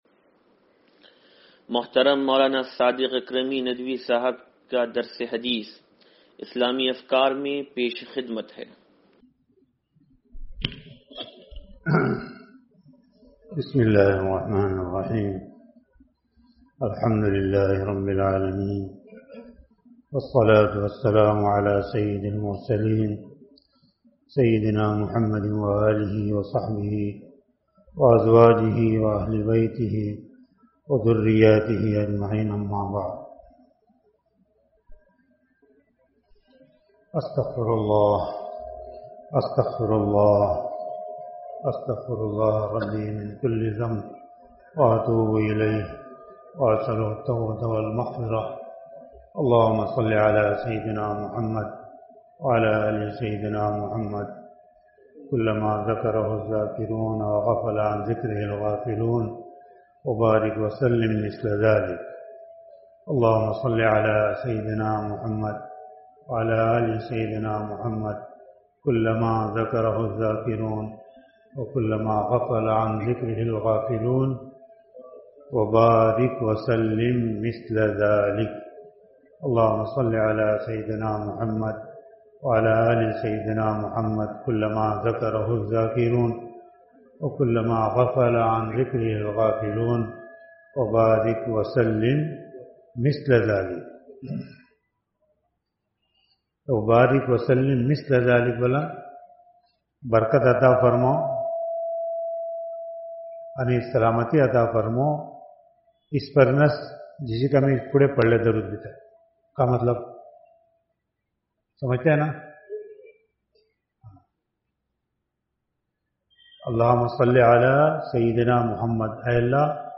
درس حدیث نمبر 0634